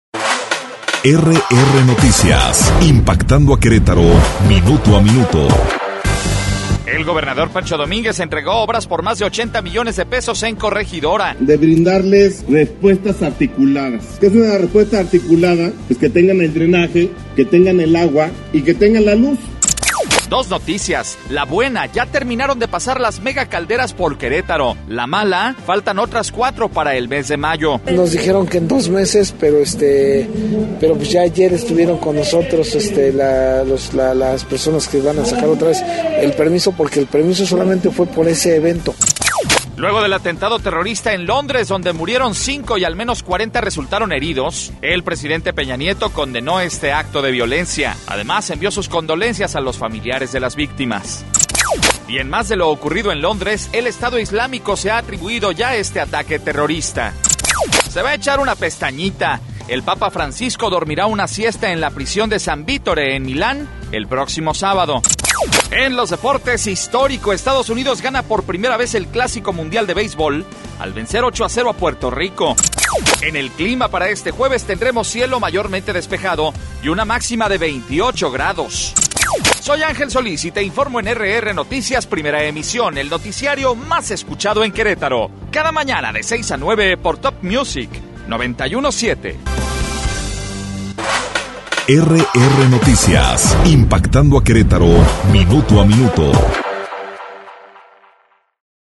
Resumen Informativo